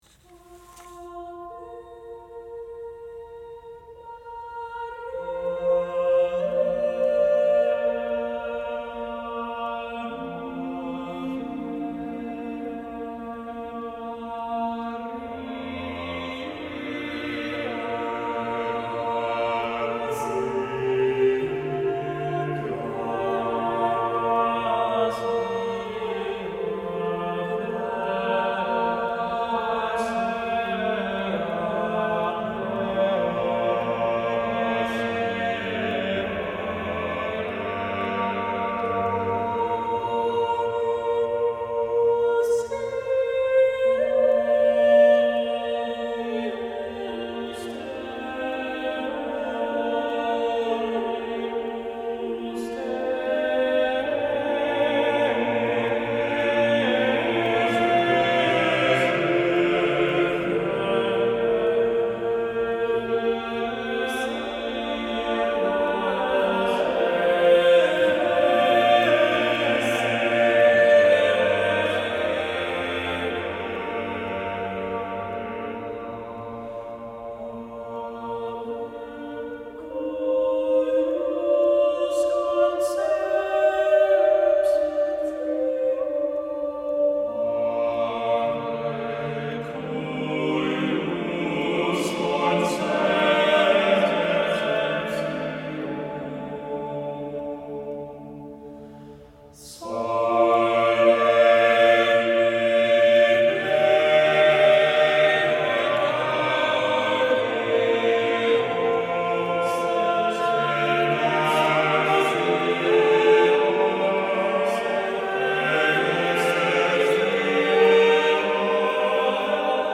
Virtuoos wordt elk tekstdeel op een andere manier gezet.
We horen hier een koraalzetting, alle stemmen hebben hetzelfde ritme, met uitzondering van de tenor, die telkens net een tel later komt.
Door zo’n verschuiving hoor je hoe dissonanten oplossen.
Hier boven hoor je het Gabriëli consort in een uiterst trage maar bijna engelachtige mystieke uitvoering.